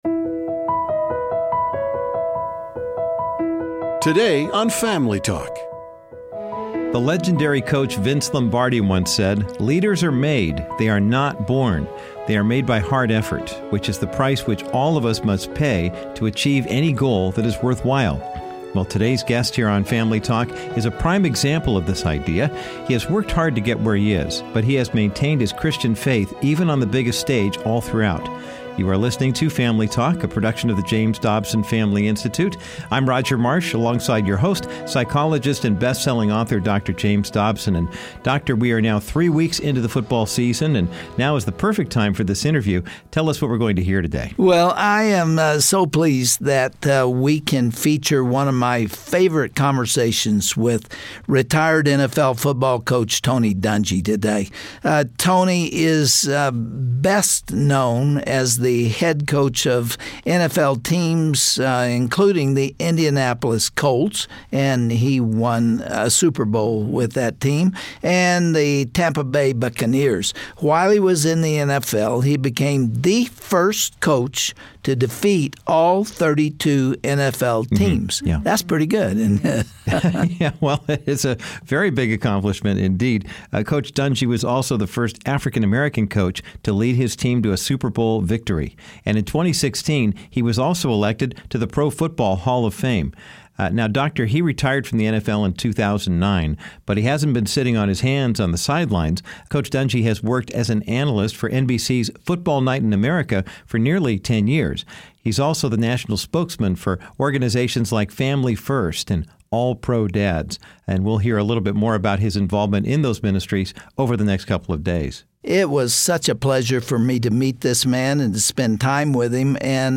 This classic edition of Family Talk features Dr. Dobsons popular conversation with another NFL coach who epitomizes godly leadership. Former Indianapolis Colts coach Tony Dungy talks about his outspoken Christian faith, which comes from his strong spiritual heritage.